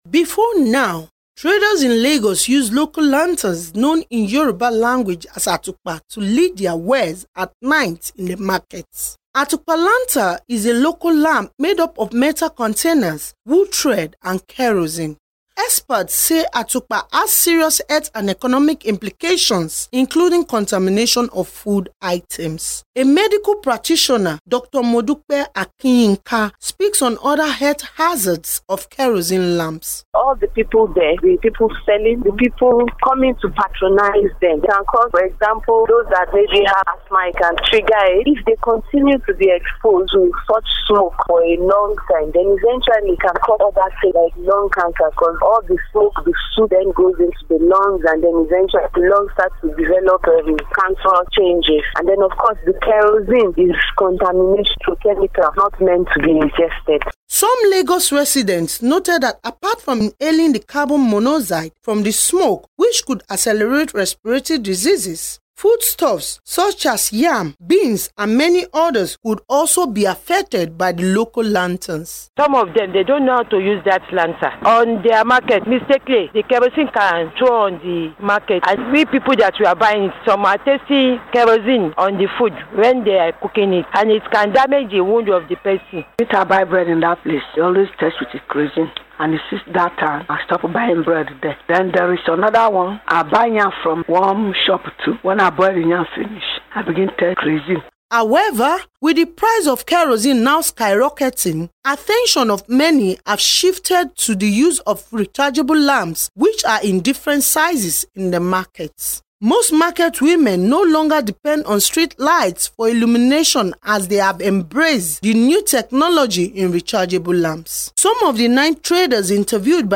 special report